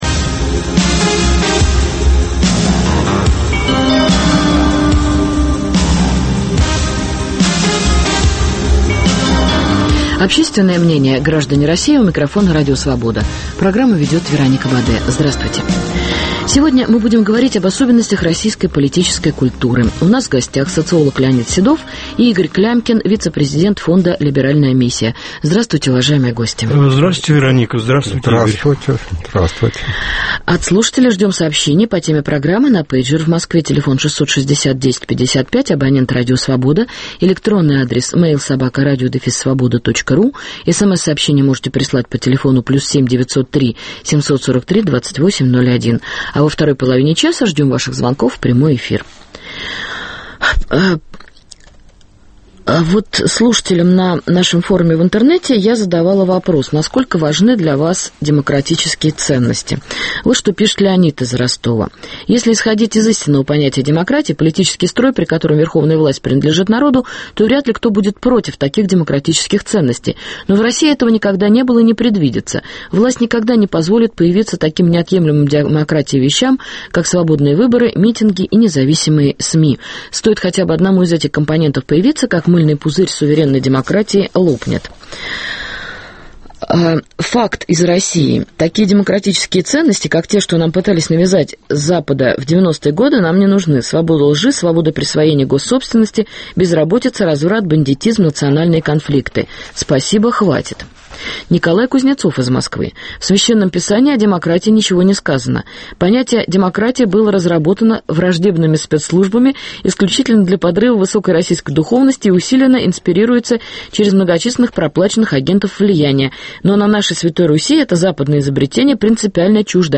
Особенности российской политической культуры. Готова ли страна воспринять демократические ценности? В гостях у Радио Свобода